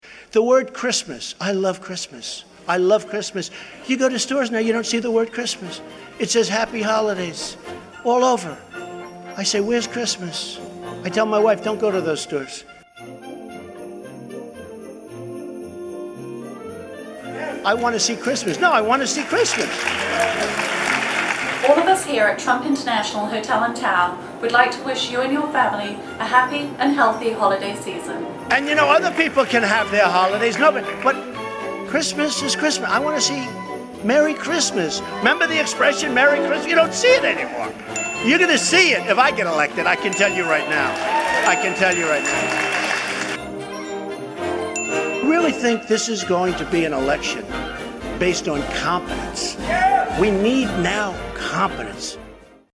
Speaking at the Values Voter Summit on September 25, 2015, Donald Trump launched a counteroffensive on The War on Christmas:
Nonetheless, the religious conservatives attending the Values Voter Summit received Trump's campaign promise warmly.